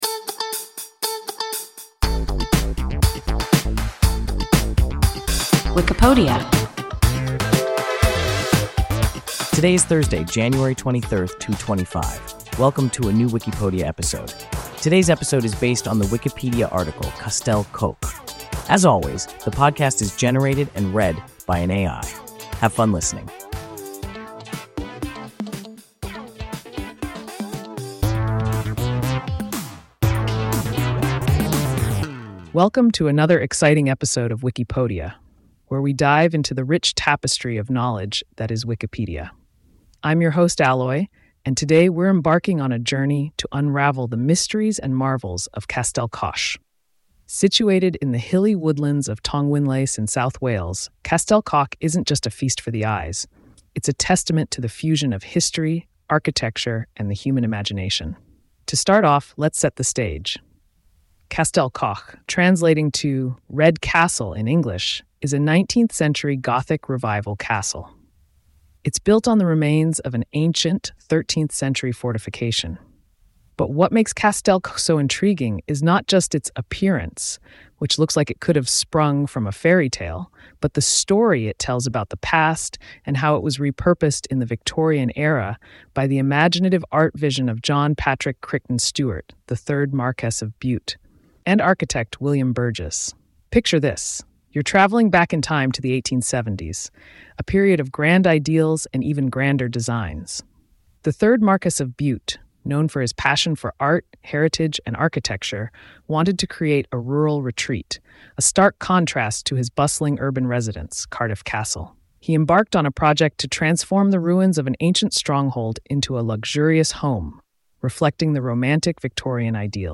Castell Coch – WIKIPODIA – ein KI Podcast